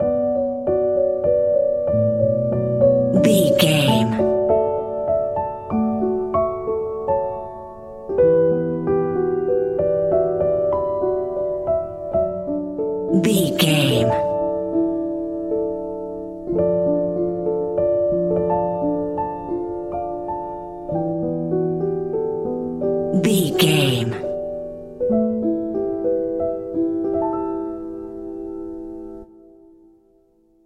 Ionian/Major
piano
contemplative
dreamy
meditative
quiet
tranquil
haunting
melancholy
ethereal